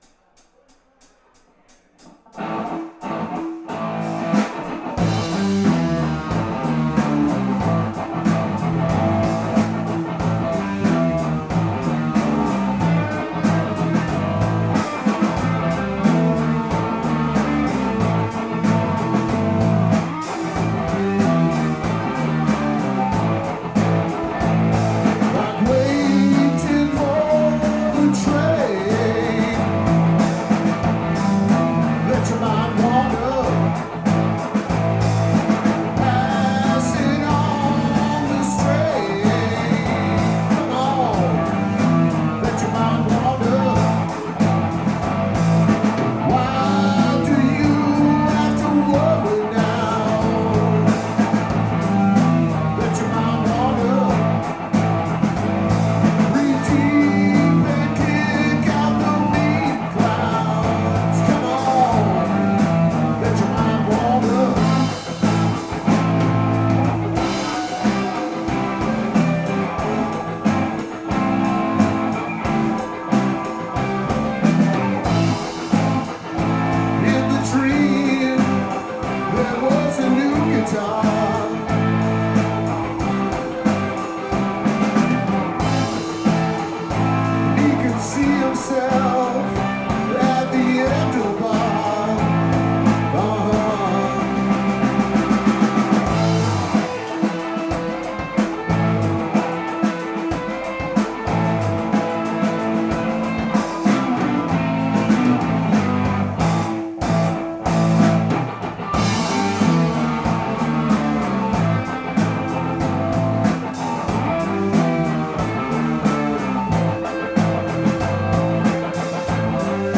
Original Rock from Worcester, MA
live at Hotel Vernon in Worcester MA